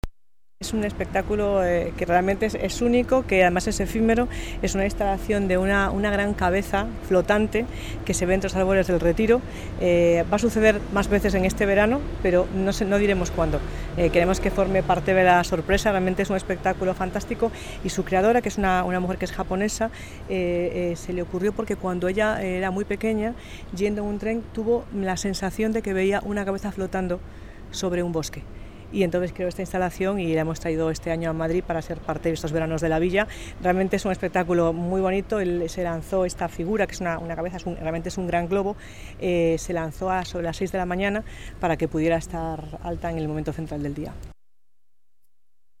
Así lo ha presentado marta Rivera de la Cruz, delegada del Área de Gobierno de Cultura, Turismo y Deporte del Ayuntamiento de Madrid: